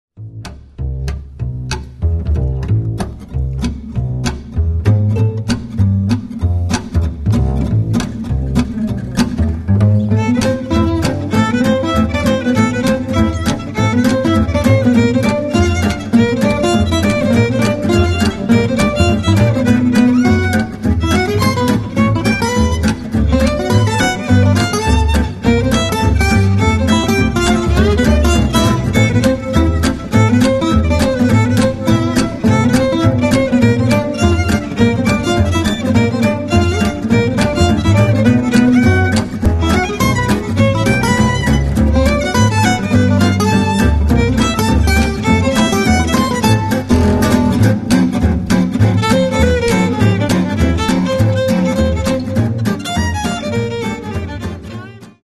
Ну а теперь издан диск с концертной записью
guitar
violin
bass